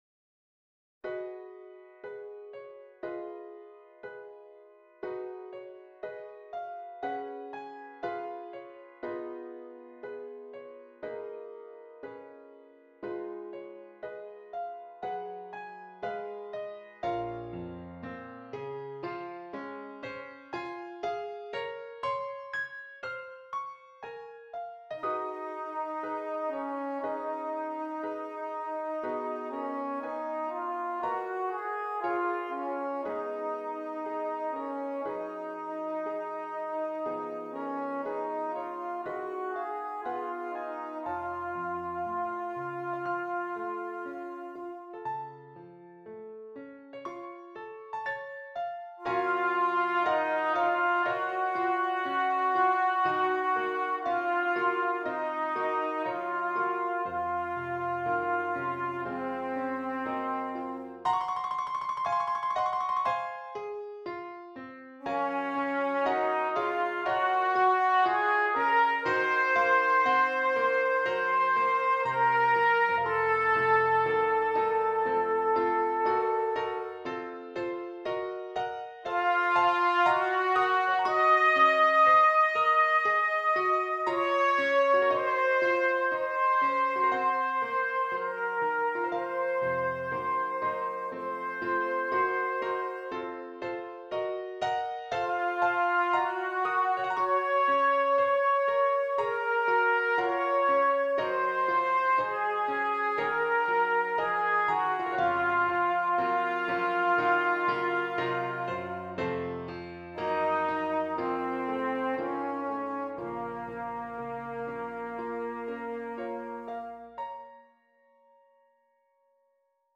is an adaptation of a work for soprano and piano.